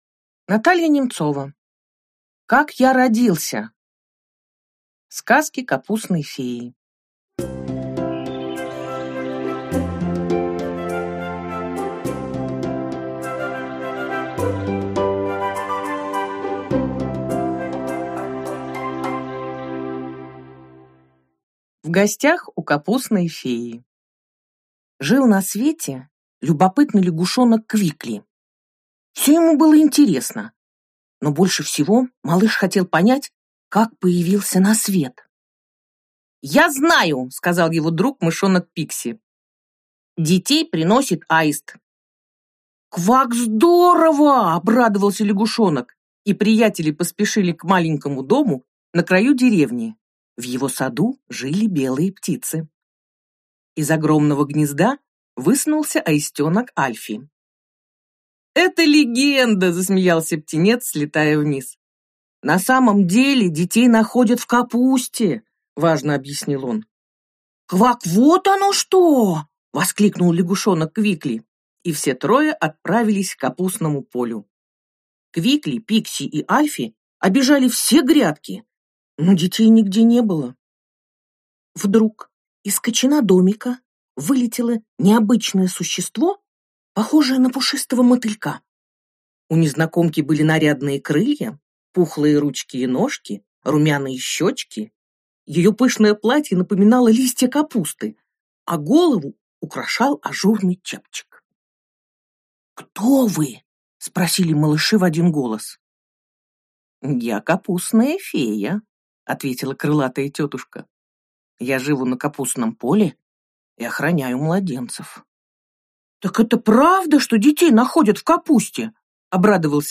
Аудиокнига Как я родился? Сказки Капустной феи | Библиотека аудиокниг